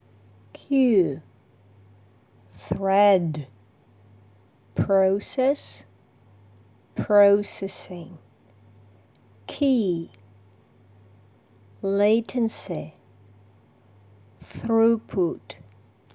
Я записала для вас голосовое, как произносить на английском вот эти слова: